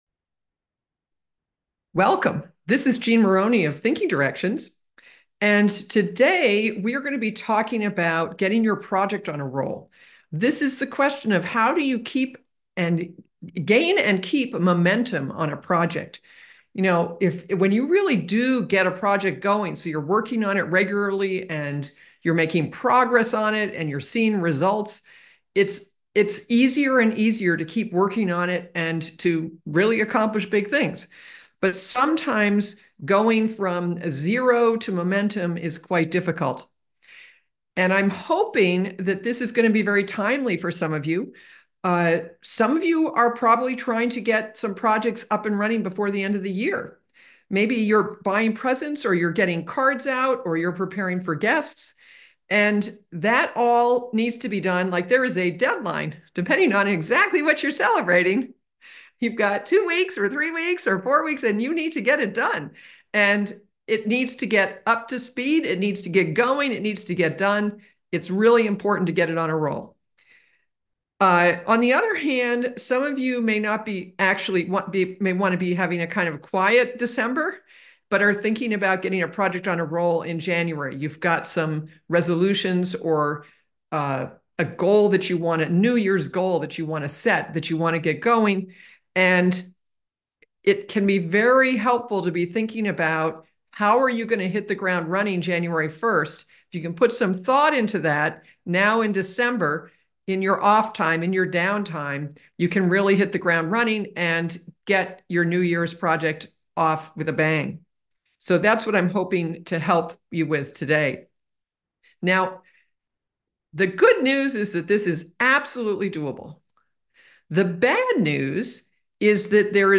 Getting Your Project On a Roll A Free 1-Hour Webinar The Handout is below.